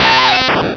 Cri d'Aspicot dans Pokémon Rubis et Saphir.